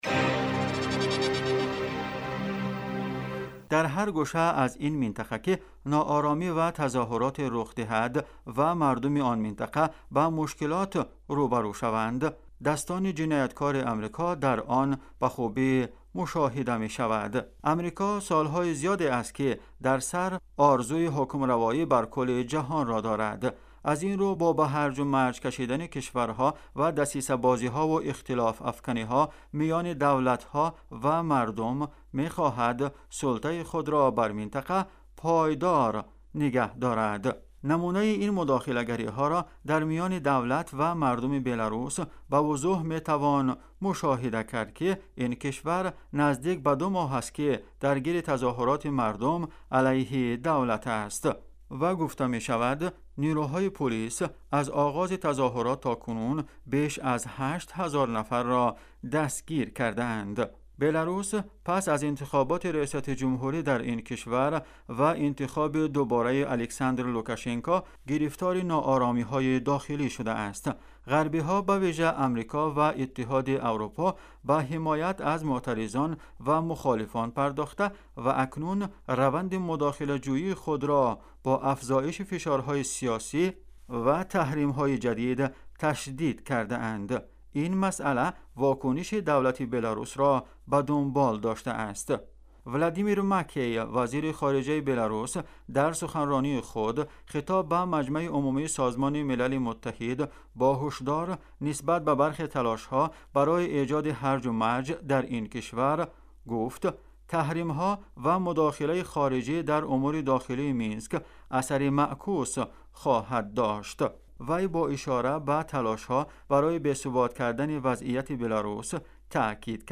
گزارش ویژه: هرج و مرج آفرینی غربی ها در بلاروس